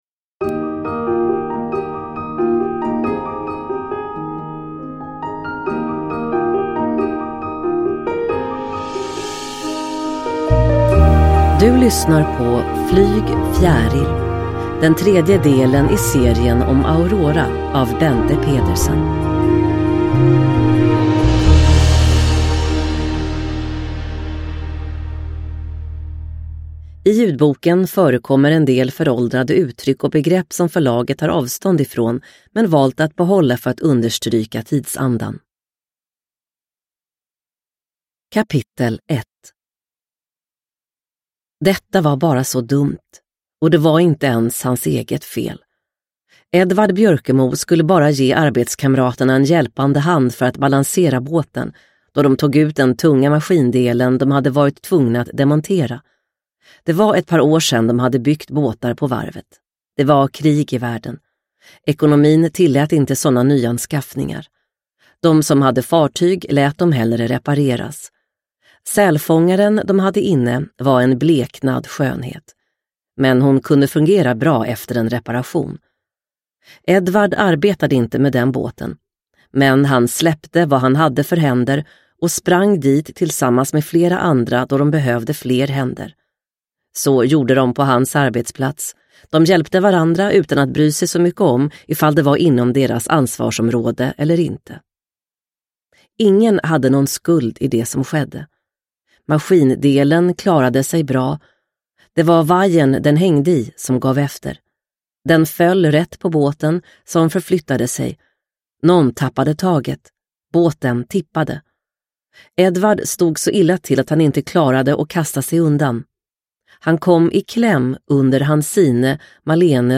Flyg, fjäril! – Ljudbok – Laddas ner